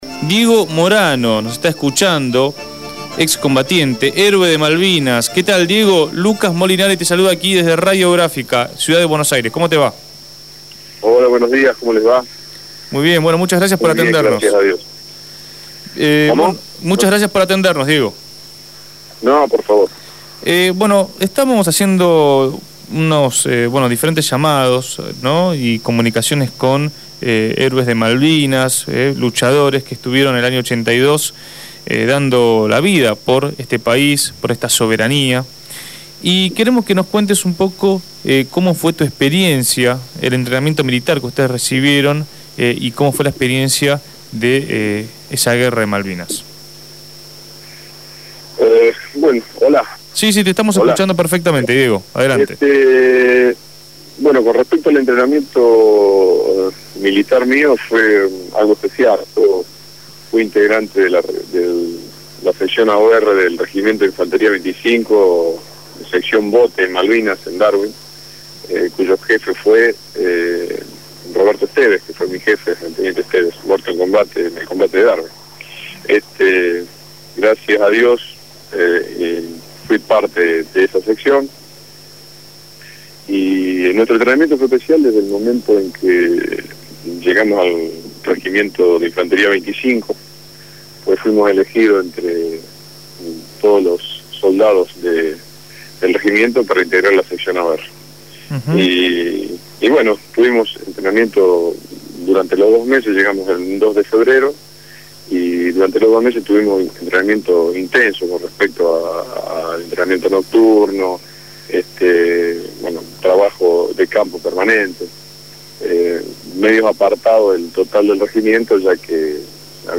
ex combatiente de Malvinas